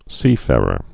(sēfârər)